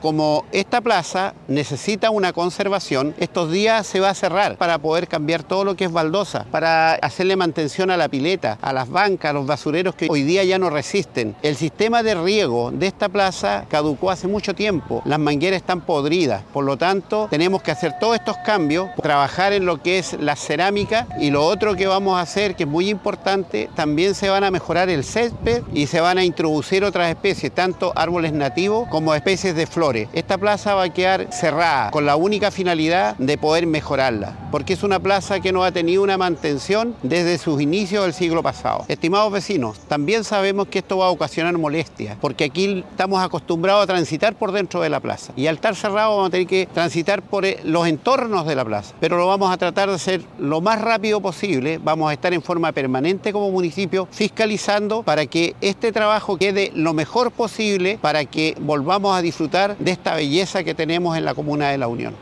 Audio-alcalde-Andres-Reinoso-1.mp3